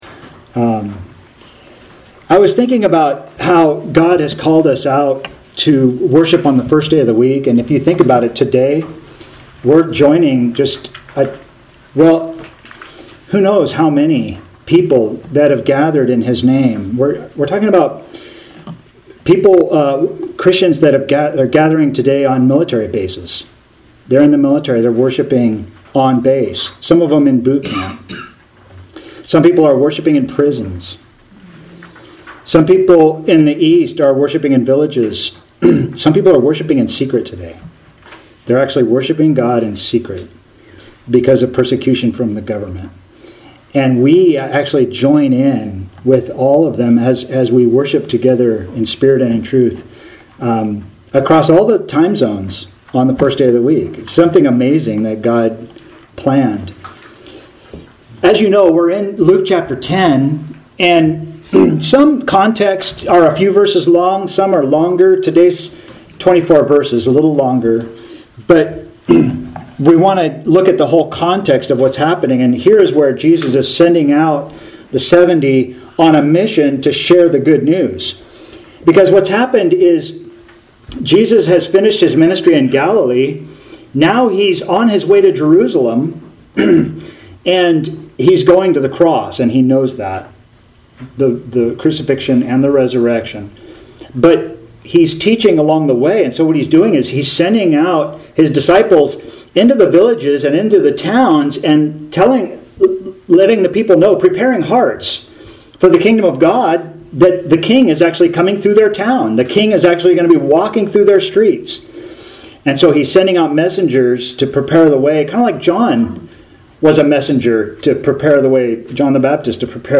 Author jstchurchofchrist Posted on November 10, 2023 Categories Sermons Tags Jesus , Luke - Gospel For All